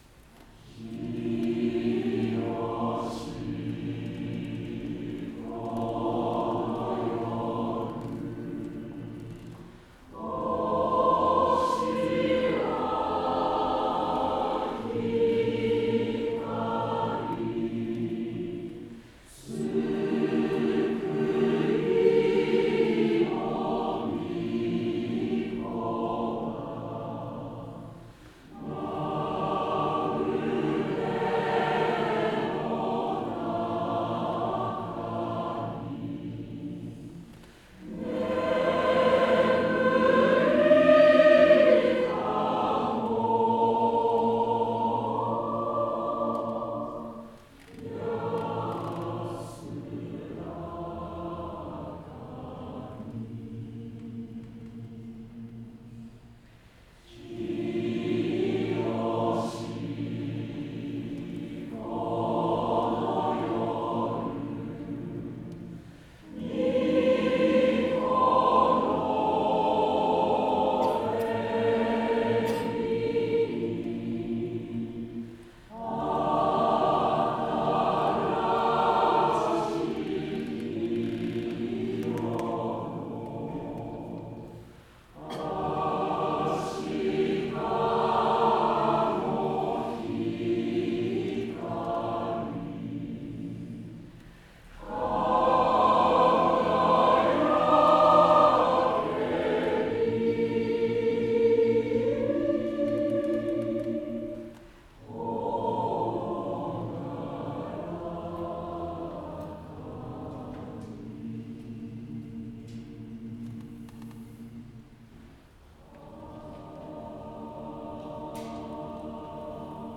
昨日行われた初めての演奏会は、小さいながらも大盛況で終えることが出来ました。
はじめての演奏会記